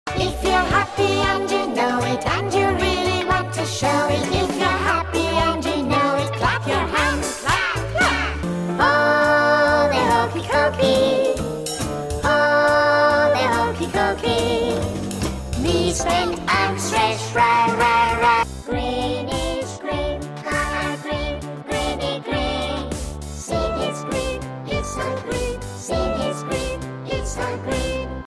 nursery rhymes